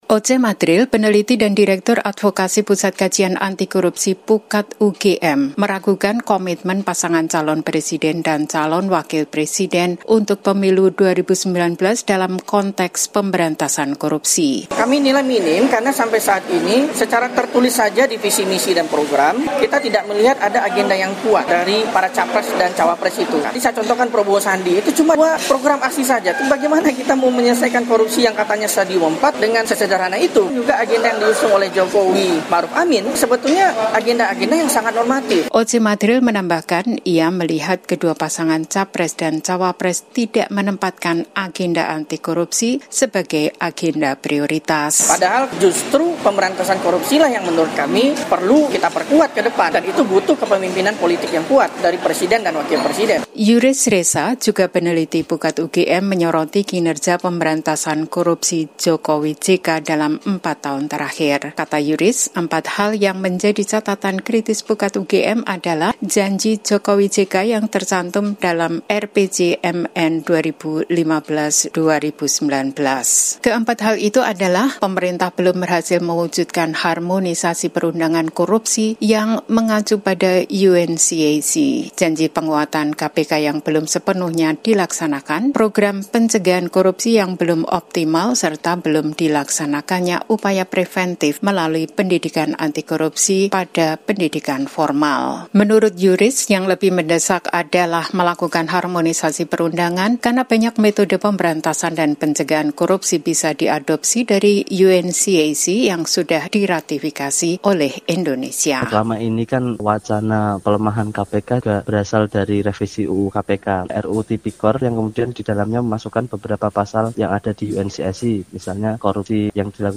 YOGYAKARTA —